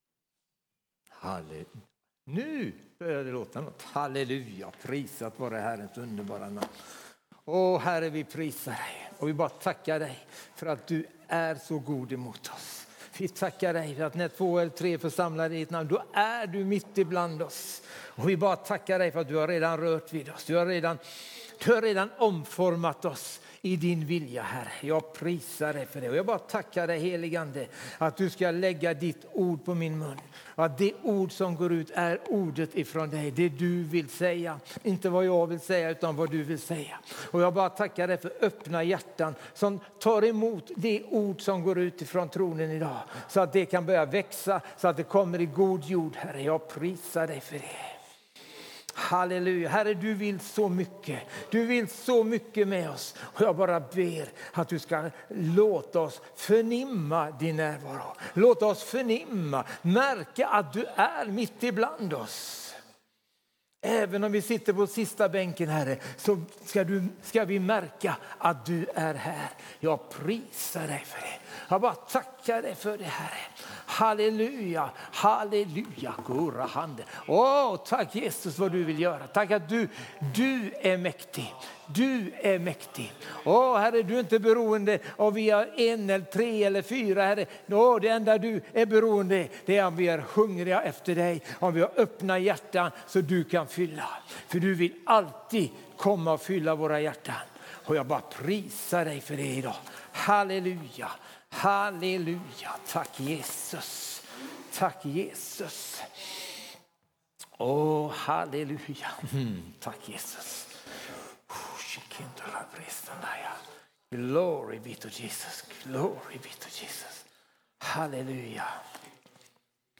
Undervisning och predikningar från Sveriges kyrkor.